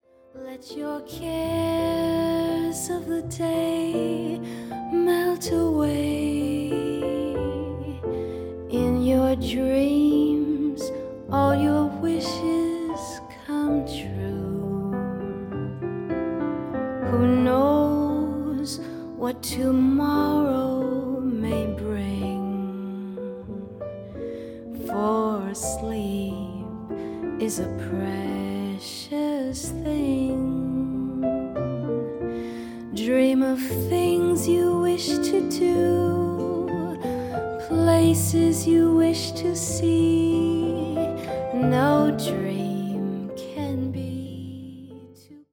vocal
bass, piano, vibraphones
gutiar
cello